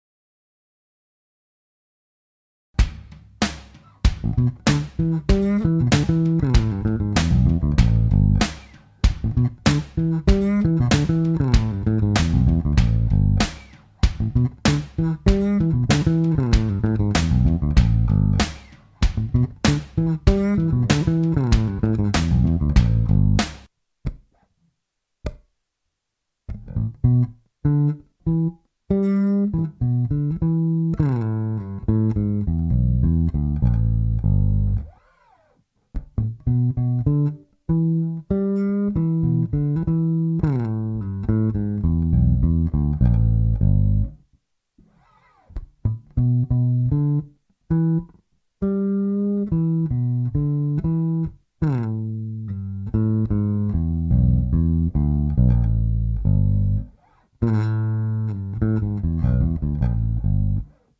L89 Blues bass lick in E7
L89-Blues-lick-in-E7.mp3